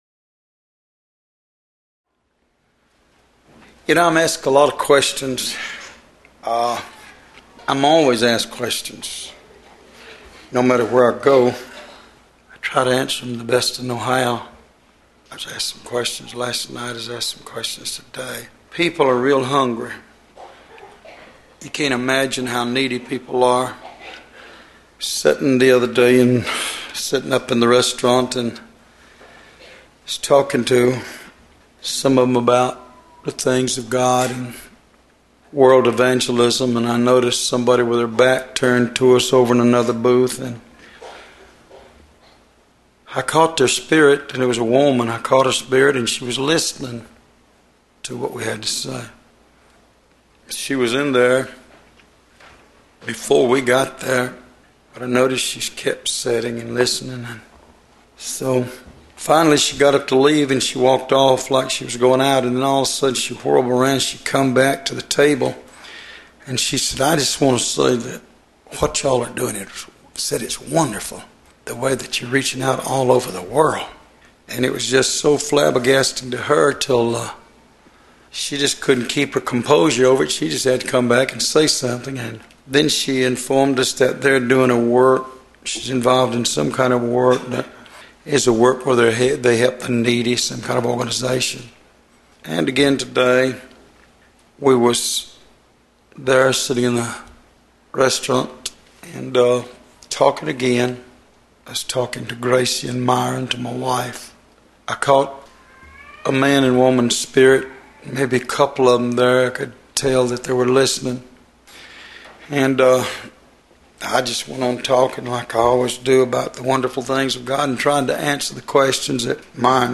Sermons Starting With ‘C’